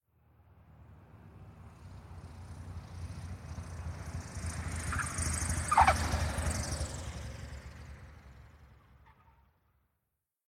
jetland.ogg